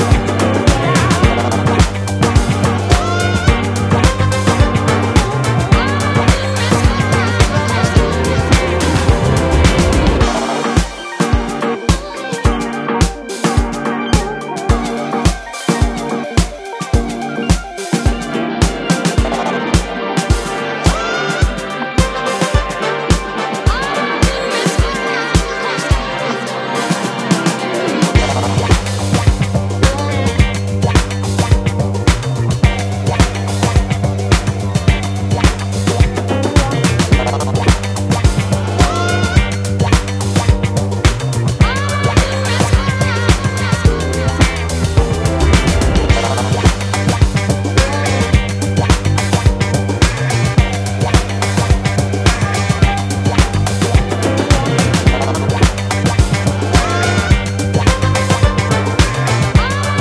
ジャンル(スタイル) ELECTRONICA / DANCE / NU JAZZ / FUNK / SOUL